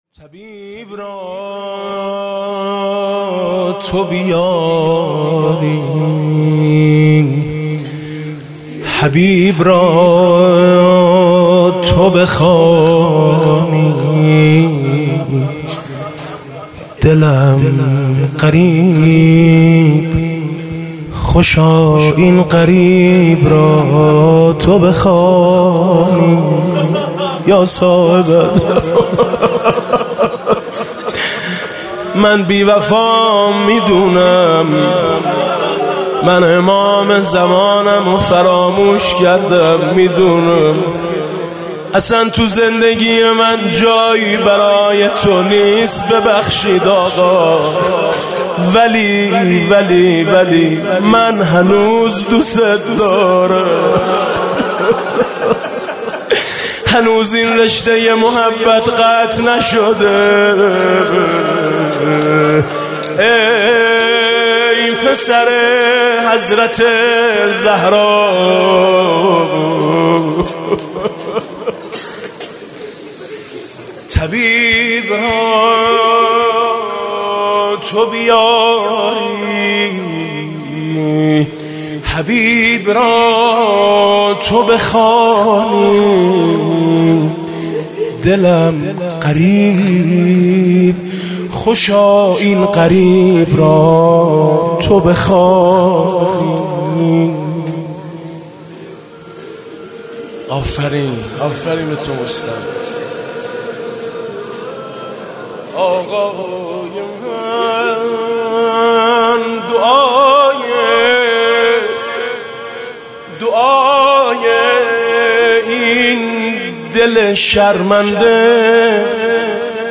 مناجات حاج میثم مطیعی